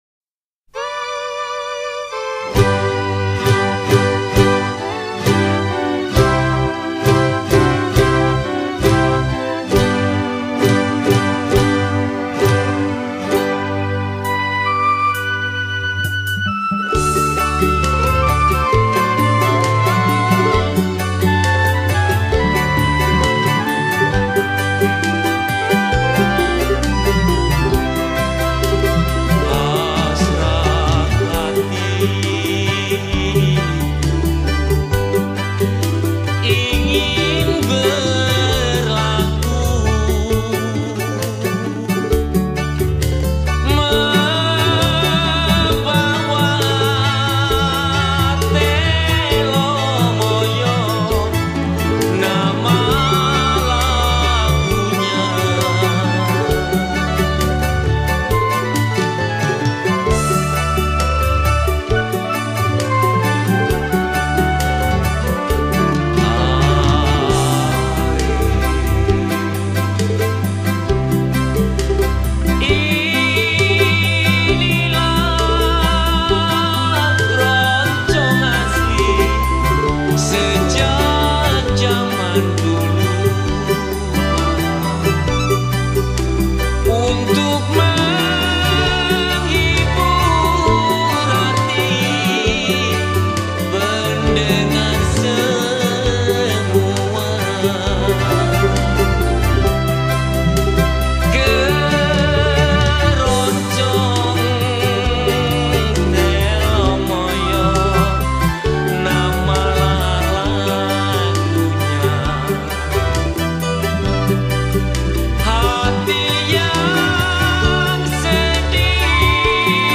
Keroncong Asli Sung By